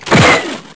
Dropbomb.wav